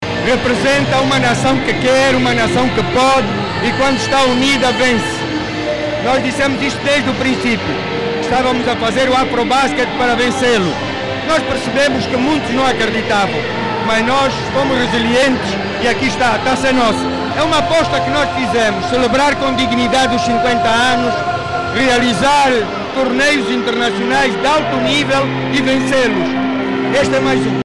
O Ministro da Juventude e Desportos, Rui Falcão Pinto de Andrade, orgulhoso, ressaltou a importância da selecção angolana ter chegado à consagração do afrobasket edição 31 quando muitos não acreditavam nessa possibilidade.